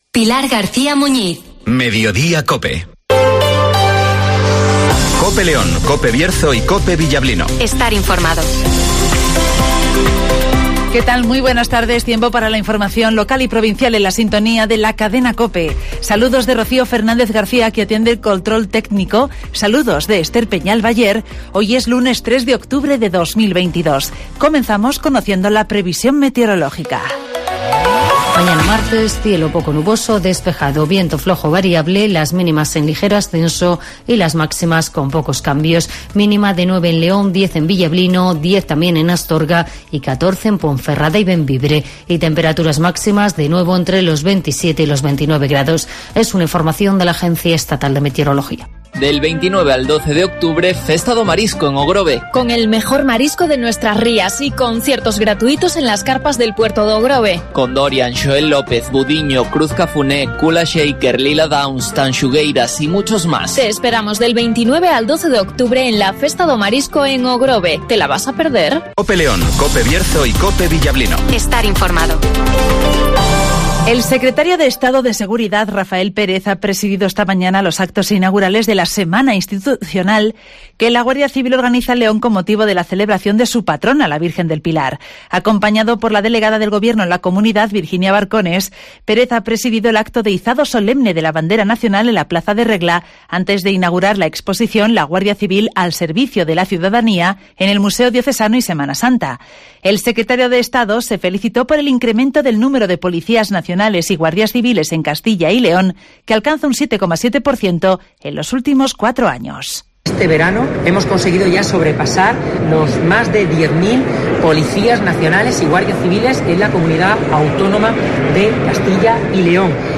INFORMATIVOS
Repaso a la actualidad informativa de León capital, del Bierzo y del resto de la provincia. Escucha aquí las noticias con las voces de los protagonistas.